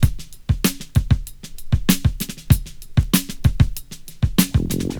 • 96 Bpm Breakbeat E Key.wav
Free breakbeat sample - kick tuned to the E note. Loudest frequency: 1904Hz
96-bpm-breakbeat-e-key-sEf.wav